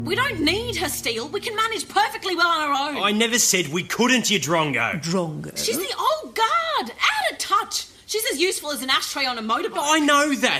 Audio drama
The new individuals speak with what seem to be Australian accents instead of British and the pair seem to have transposed personalities, with Sapphire being impatient, stern, and often rude and Steel being more kind-hearted; but they each seem to have the same individual abilities.
Memorable Dialog